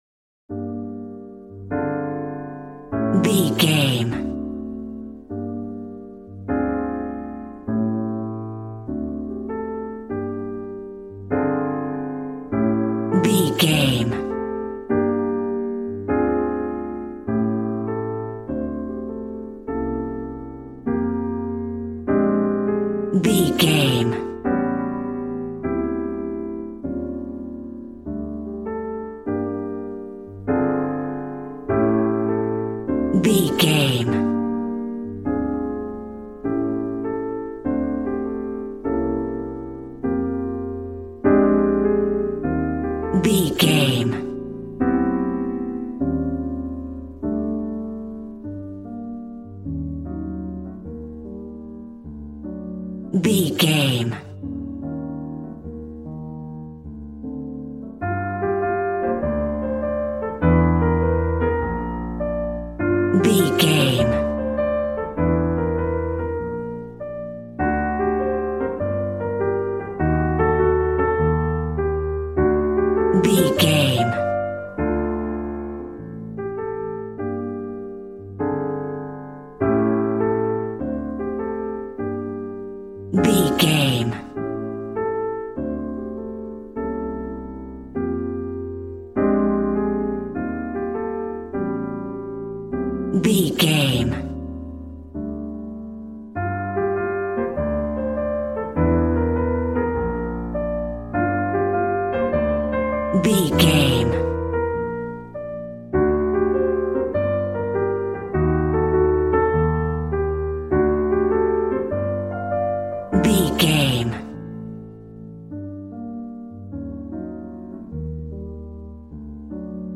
Smooth jazz piano mixed with jazz bass and cool jazz drums.,
Aeolian/Minor